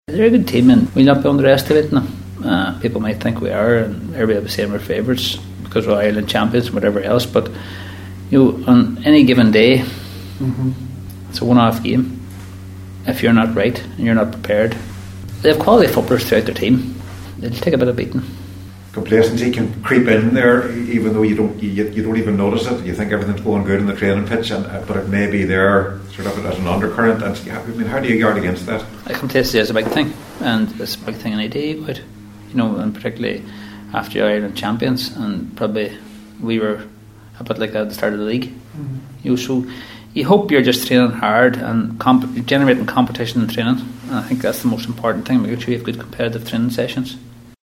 Tyrone will be raging hot favourites for the game but joint Manager Brian Dooher says they can’t take the game or opposition for granted: